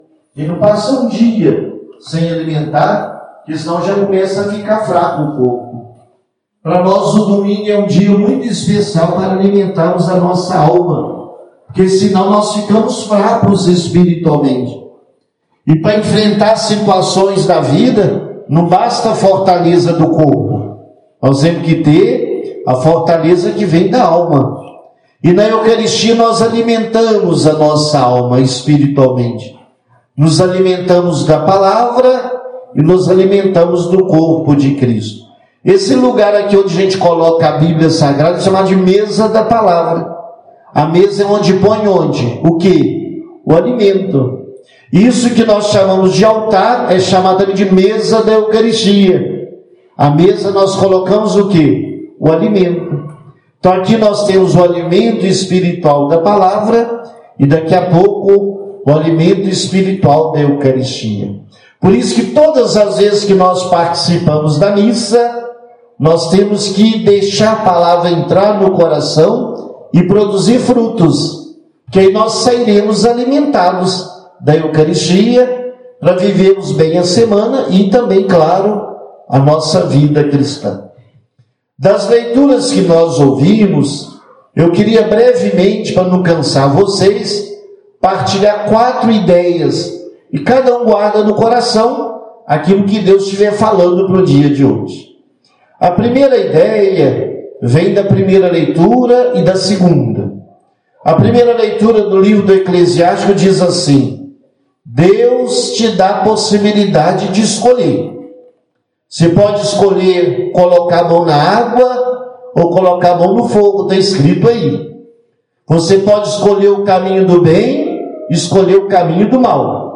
Igreja de Nossa Senhora Aparecida
Homilia.mp3